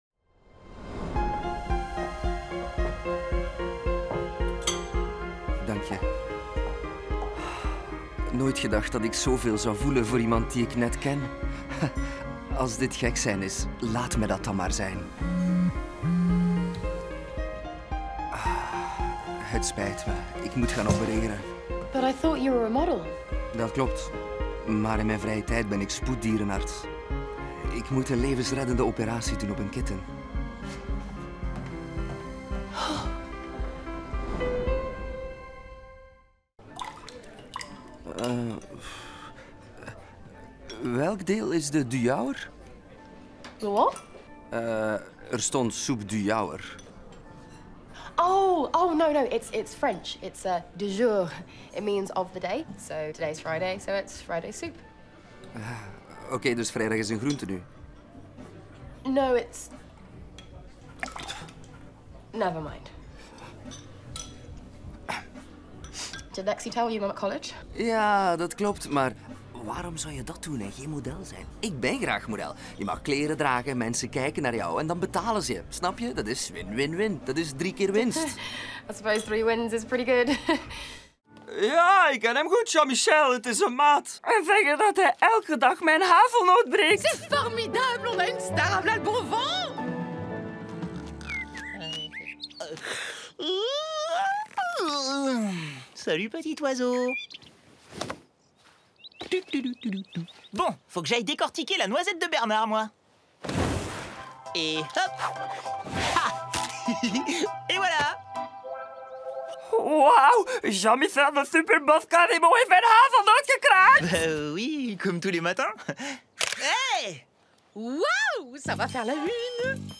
Sprecher
Kommerziell, Junge, Natürlich, Urban, Cool
Persönlichkeiten